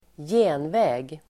Uttal: [²j'e:nvä:g el. ²ji:nvä:g]